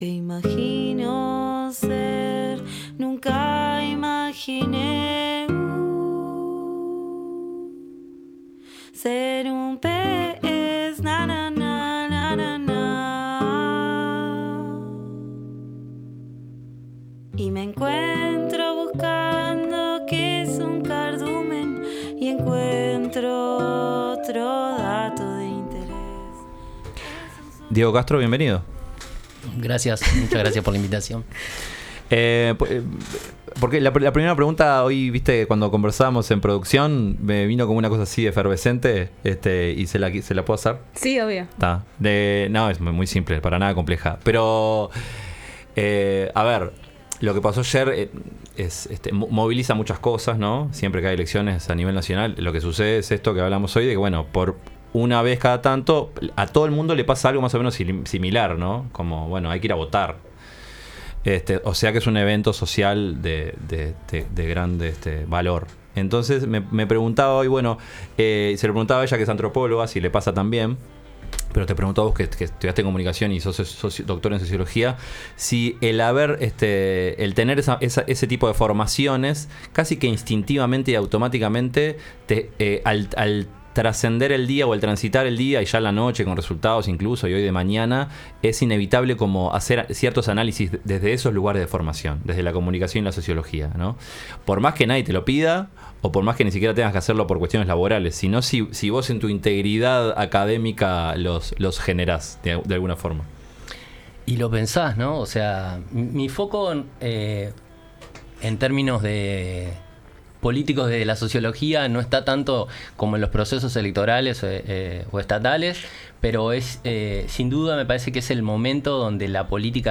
Escaramujo #5: Entrevista